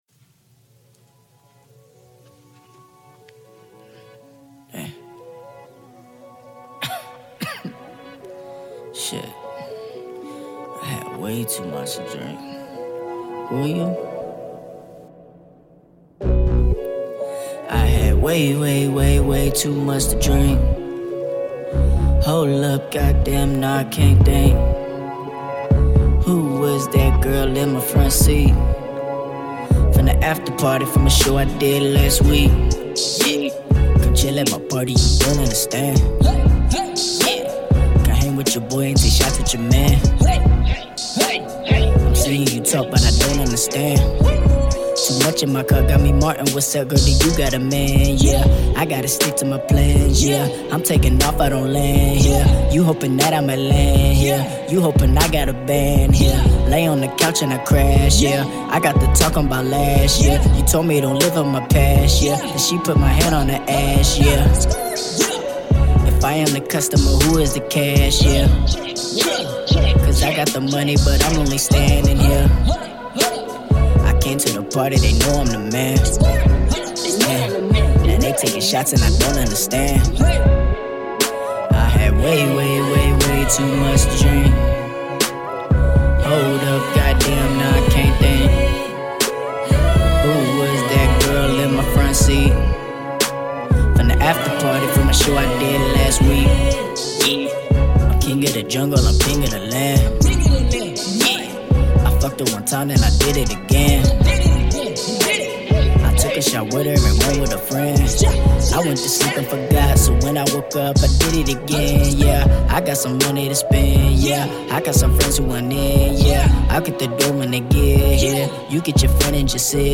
raised in Columbus Georgia were he became a hip/hop artist